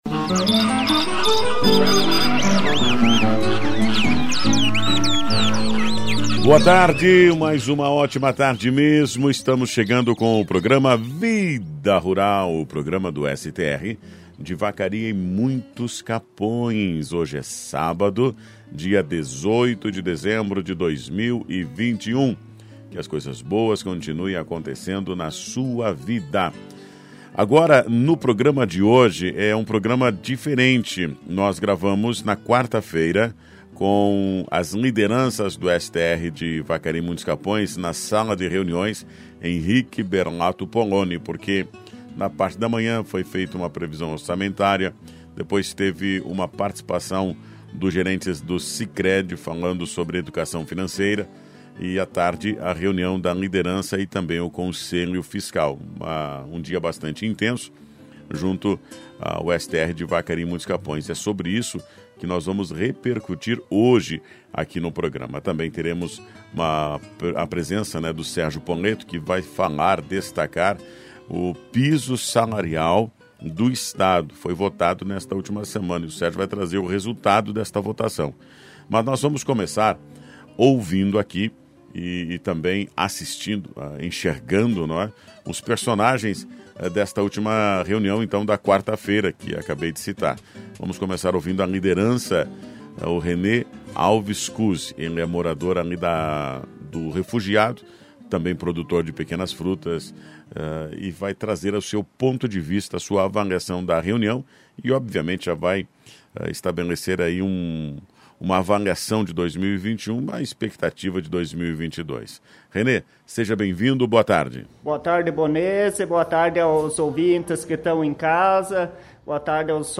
Informativo do STR de Vacaria e Muitos Capões. Nesse programa com a participação das Lideranças Rurais e representantes do Sicredi